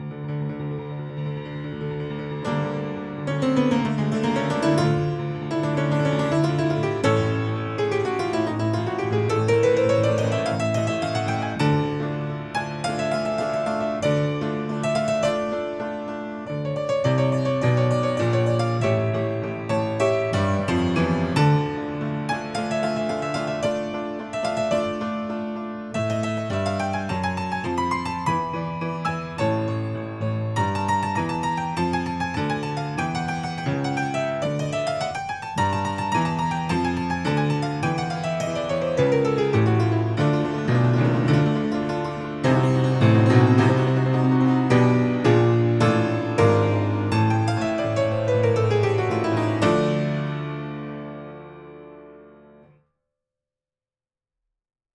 Notes: for piano
Allegro-Agitato–D.